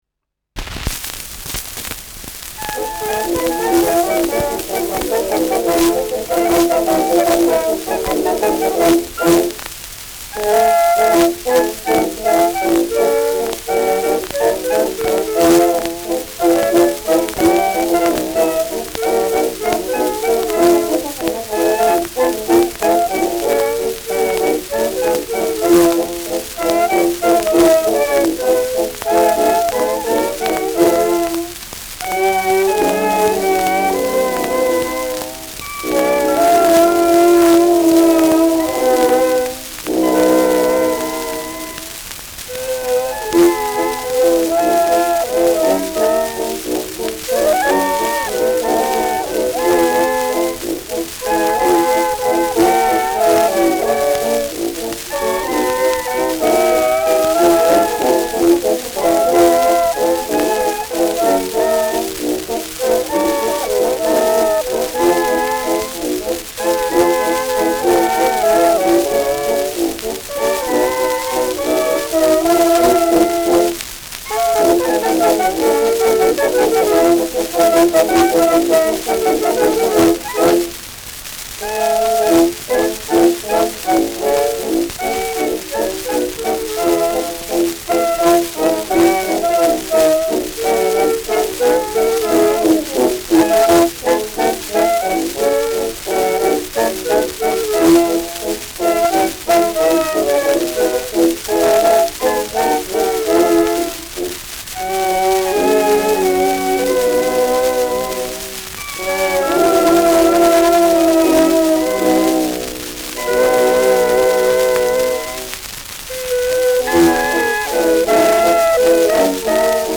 Schellackplatte
präsentes Rauschen : Knacken